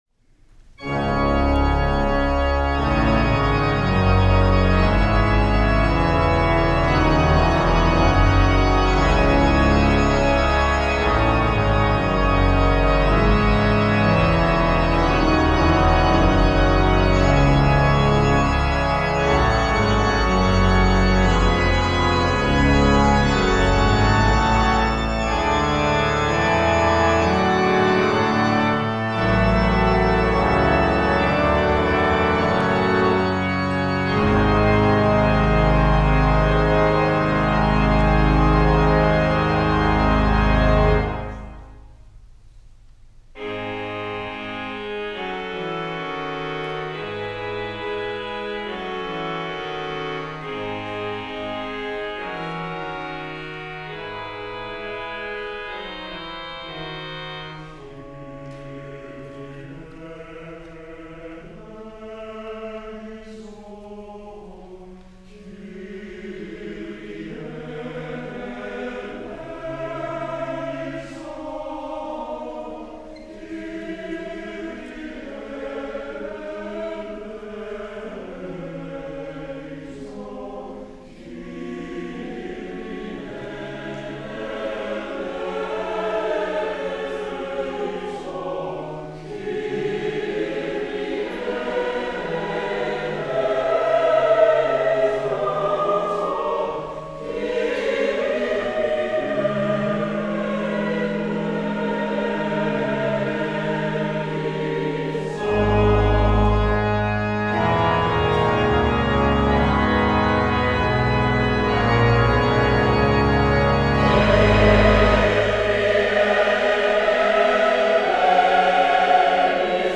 Written for choir and two organs, it was dedicated to Théodore Dubois, a French composer and organist. We are blessed to have a venue at the First Presbyterian Church, San Anselmo, where two pipe organs are present, to perform this work as it was originally intended.